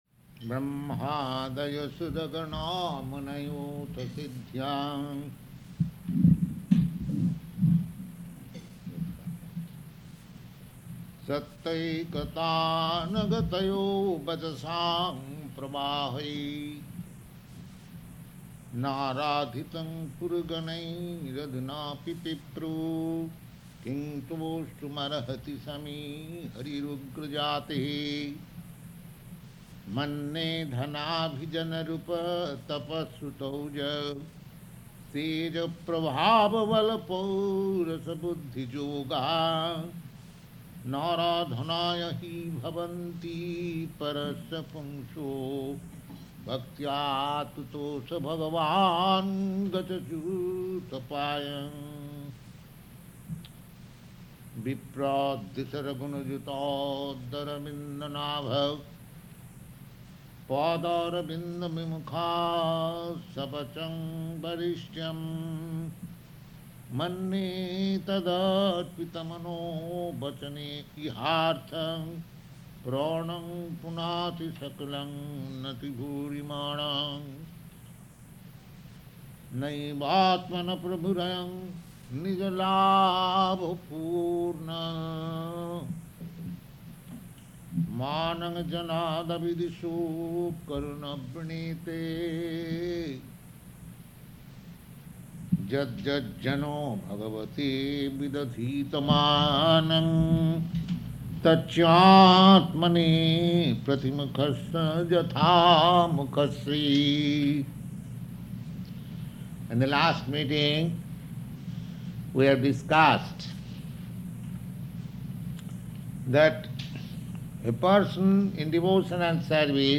Location: Hawaii